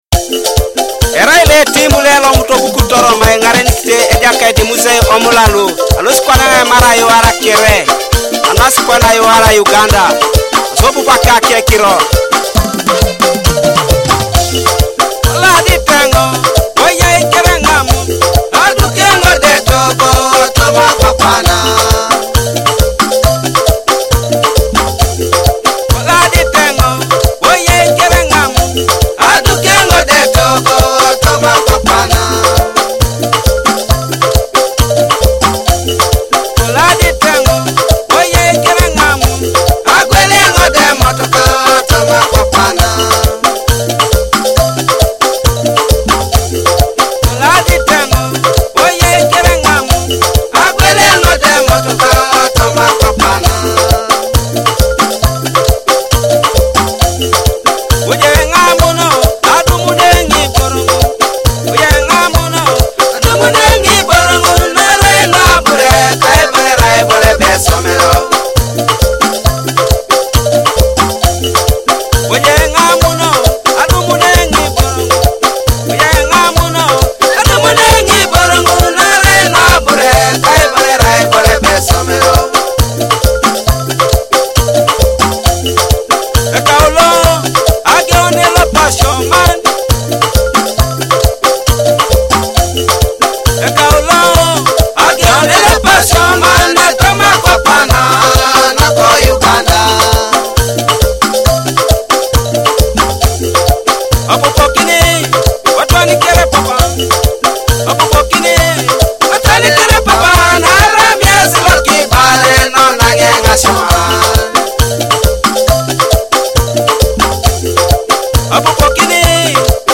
Teso cultural and traditional rhythms in Akogo and Adungu